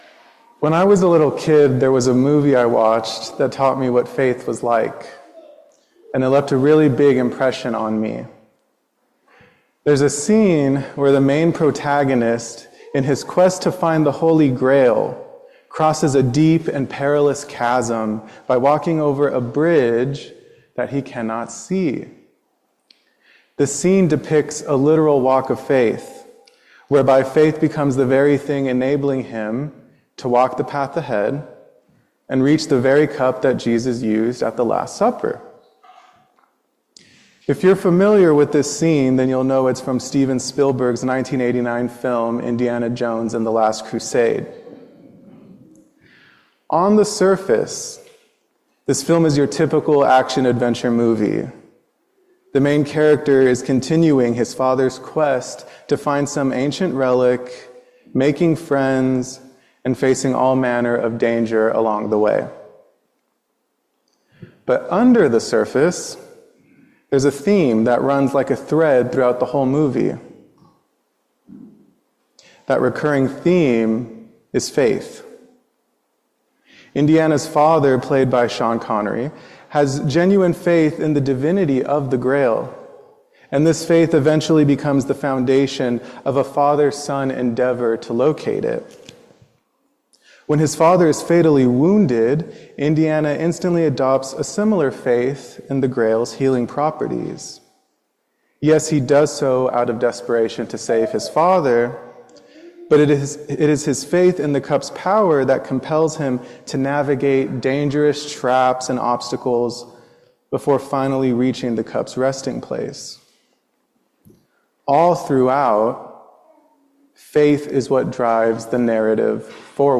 Sermon on August 10, 2025“Led by Faith”By